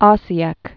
sē-ĕk, -yĕk)